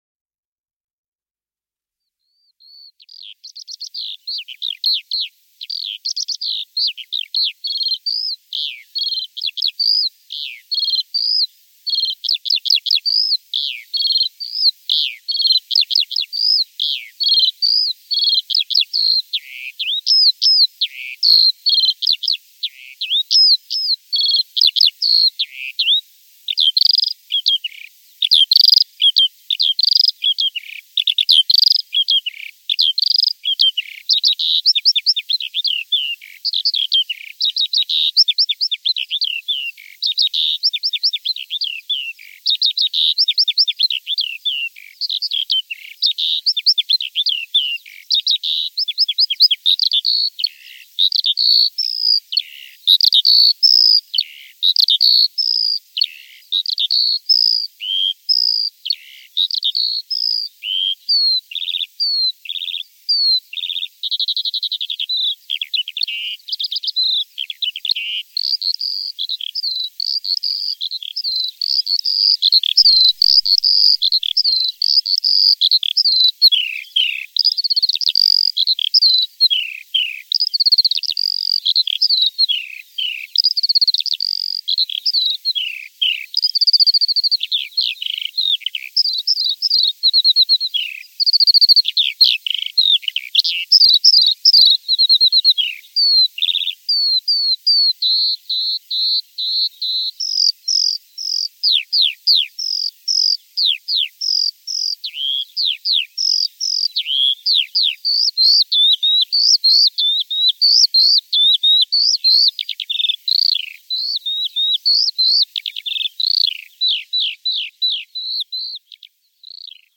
Heidelerche
Hier erfreuen uns die lustigen S�nger mit ihren wohlklingenden, trillernden Gesang genauso, wie die buntgefiederten Waldbewohner.